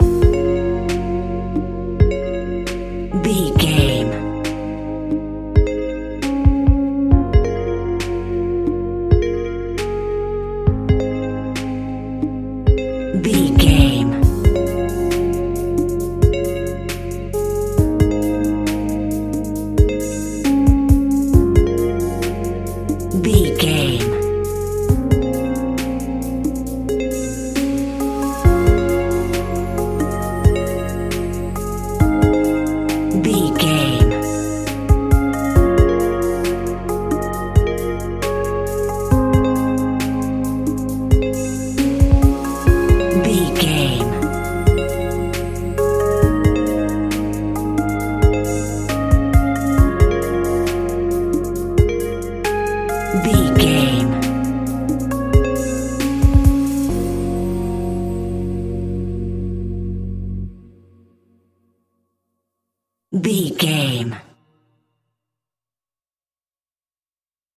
Aeolian/Minor
B♭
Slow
Elecronica Music
laid back
groove
hip hop drums
hip hop synths
piano
hip hop pads